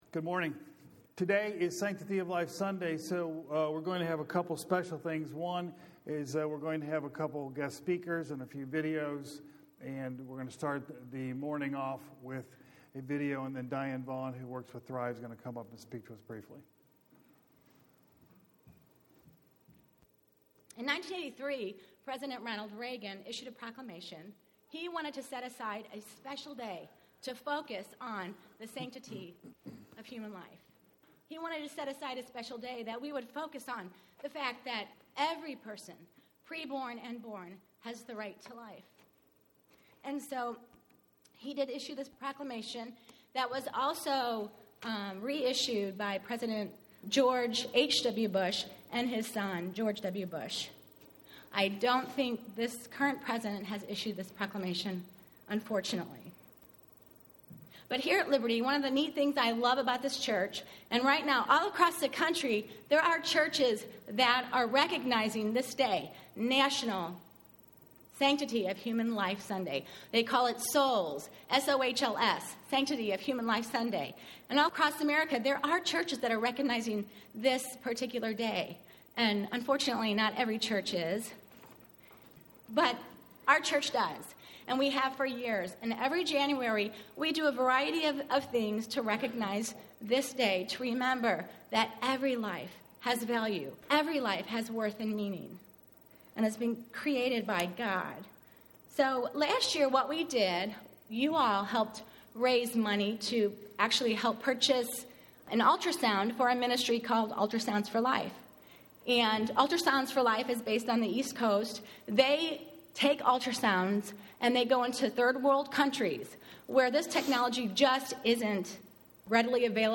Sanctity of Life Sunday 2011